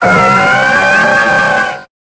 Cri de Milobellus dans Pokémon Épée et Bouclier.